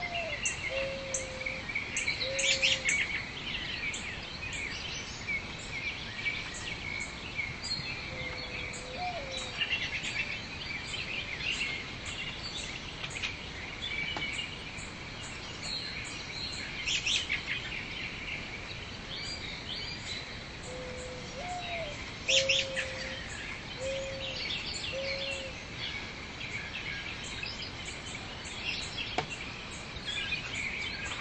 描述：鸟儿为阿拉巴马州3月中旬的一个早春早晨增添了氛围。